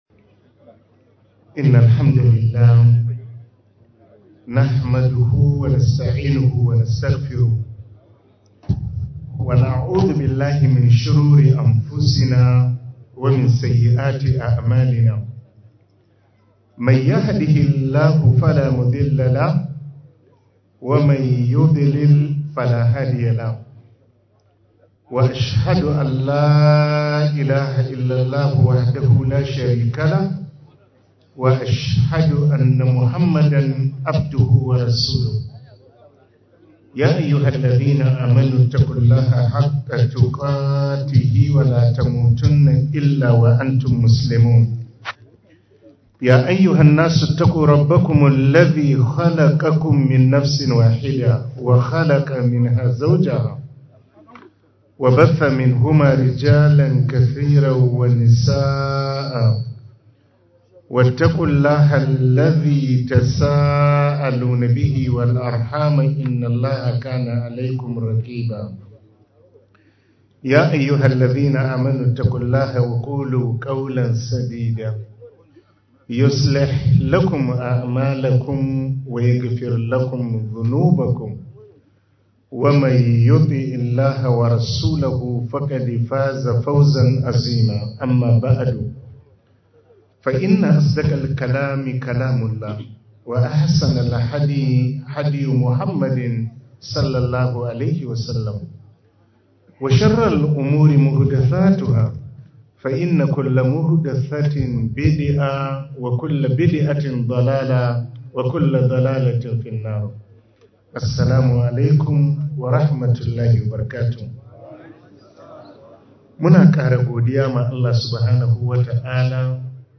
Bayar Da Gudumawa wa Addini - Ivory Coast